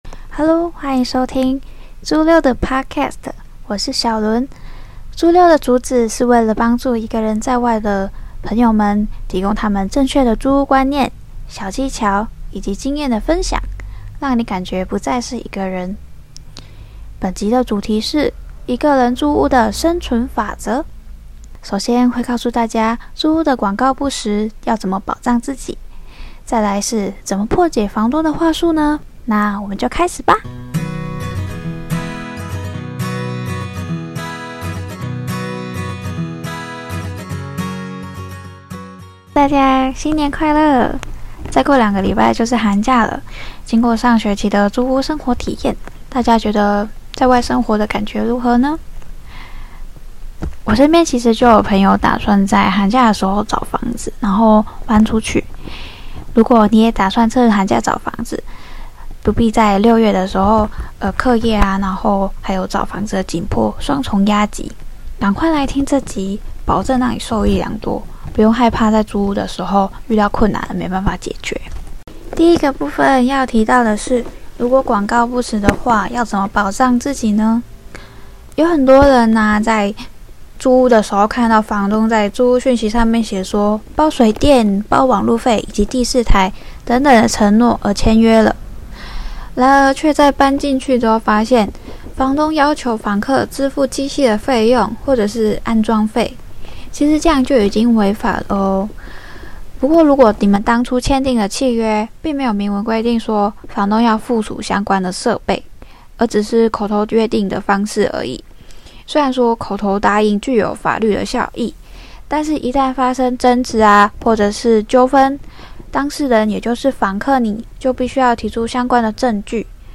這部Podcast中的配樂：Royalty Free Music from Bensound-sunny、Royalty Free Music from Bensound-thelounge